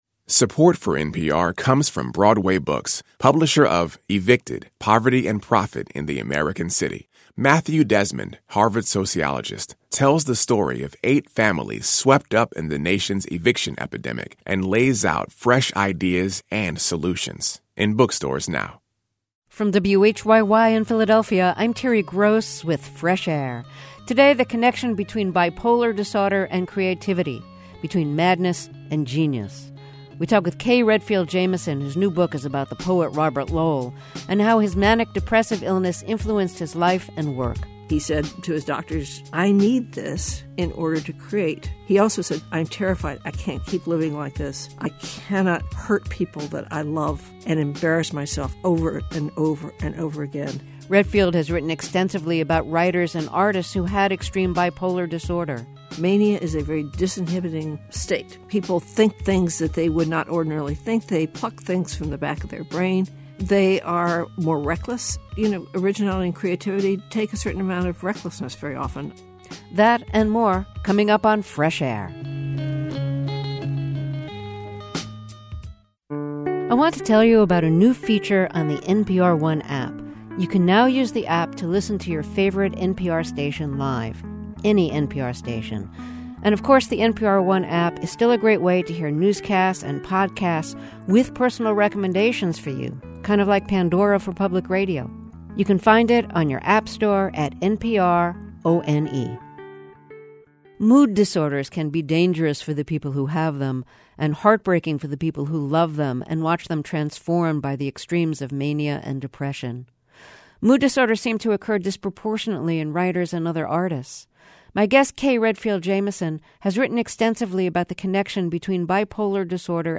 Listen to the fifty minute audio interview in NPR